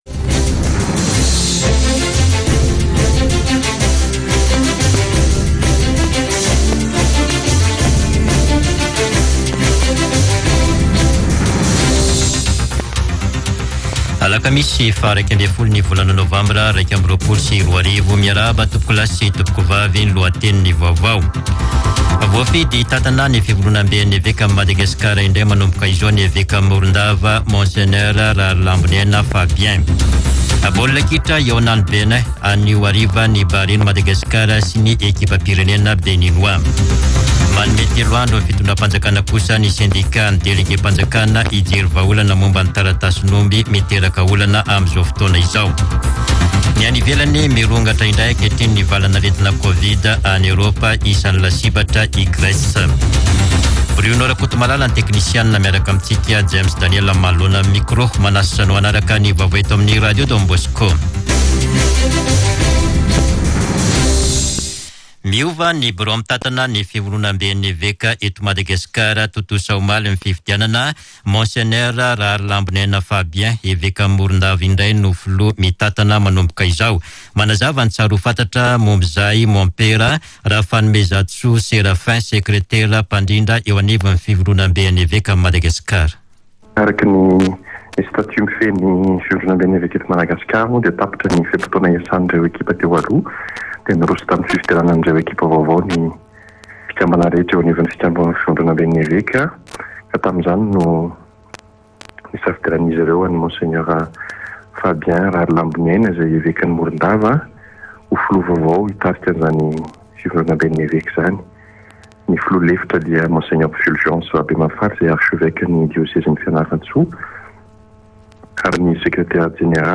[Vaovao maraina] Alakamisy 11 novambra 2021